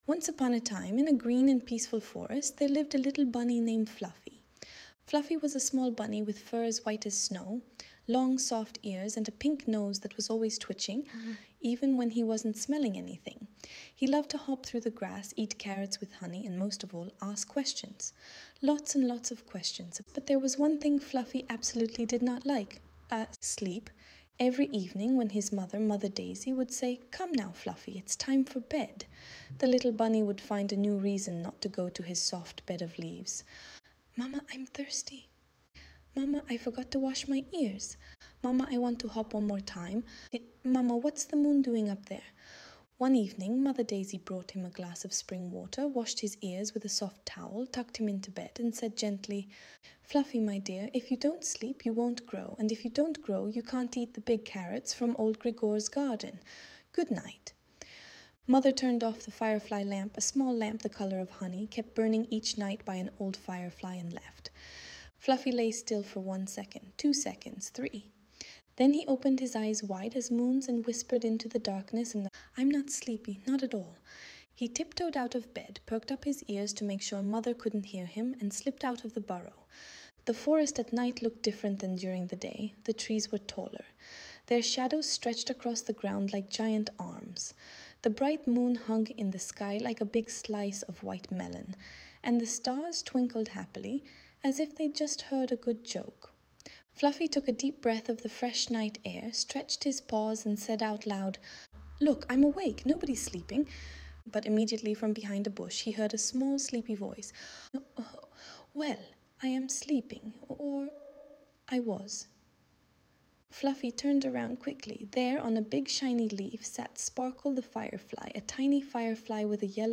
audiobook The Bunny Who Wouldn't Sleep - A Magical Bedtime Story
audiobook_bunny_named_Fluffy.mp3